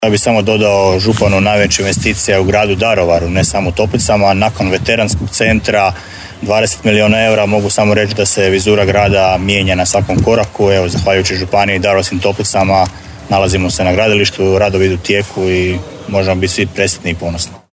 Na svakom koraku u Daruvaru popunjava se prostor novim sadržajima i kako kaže gradonačelnik Damir Lneniček: